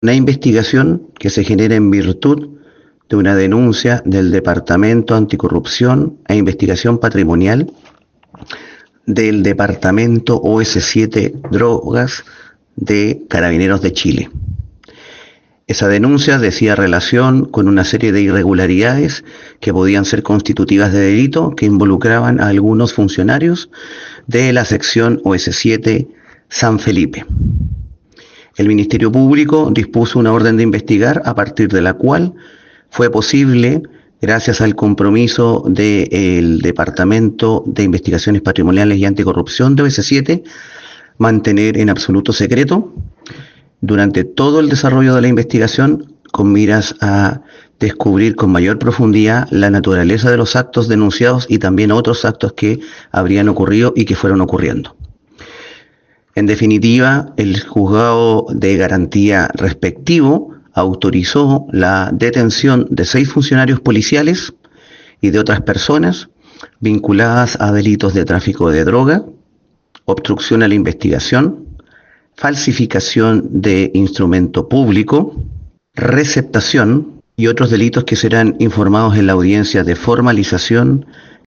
El Fiscal Maximiliano Krause Leyton, explicó de qué forma comenzó a investigarse a este  cartel de policías corruptos.